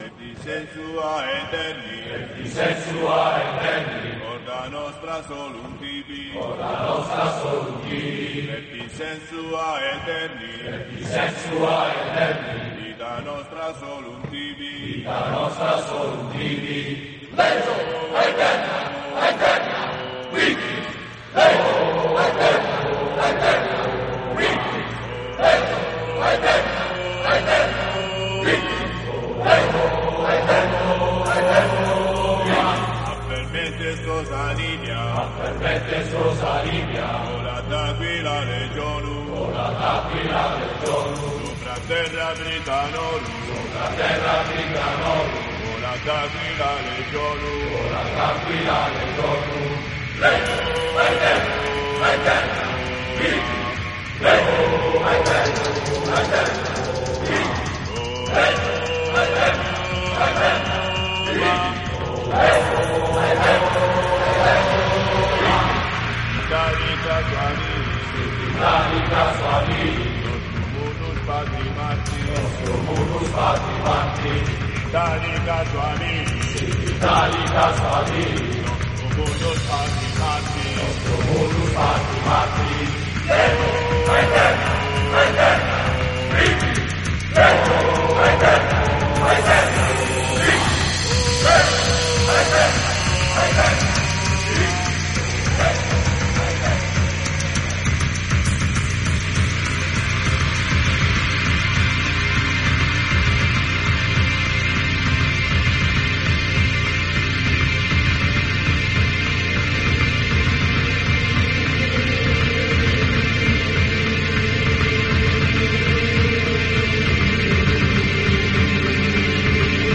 ست الکترونیک